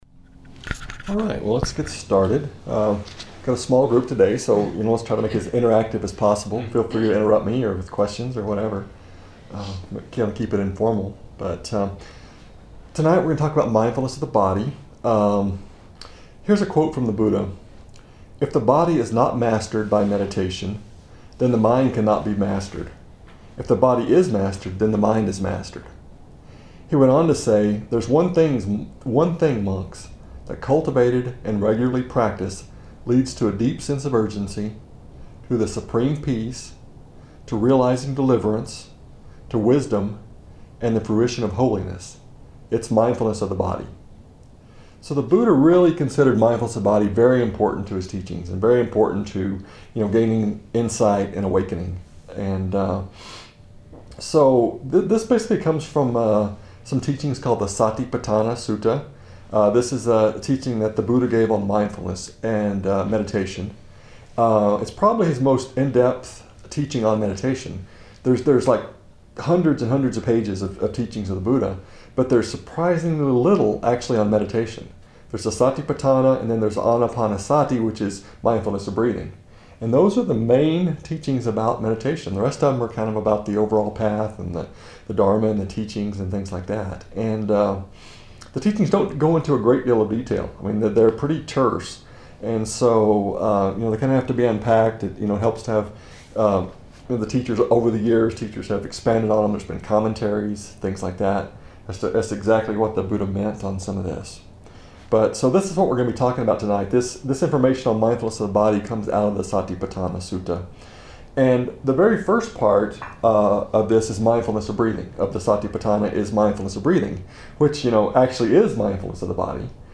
Dharma Treasure Talk in Tucson
at the Tucson Community Meditation Center